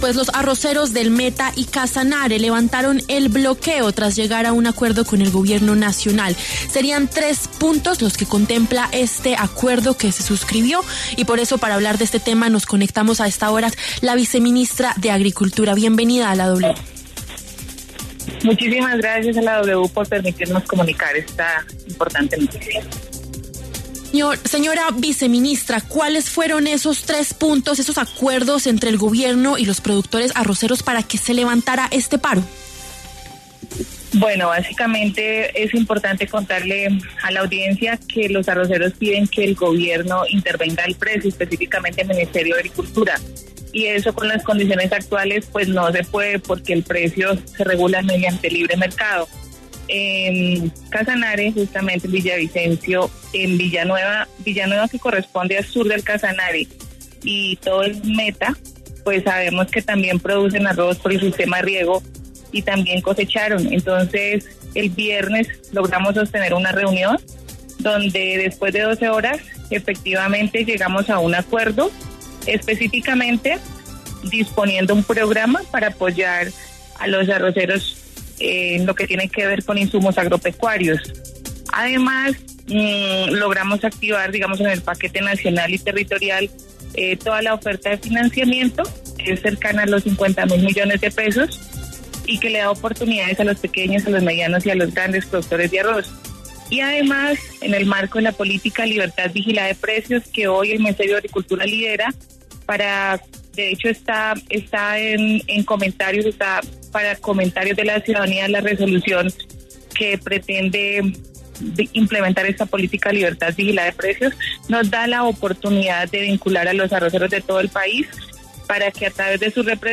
Geidy Ortega, viceministra de Agricultura, dialogó con W Fin De Semana a propósito de la decisión de los arroceros del Meta y Casanare de levantar los bloqueos y el paro por el bajo precio del arroz en el país.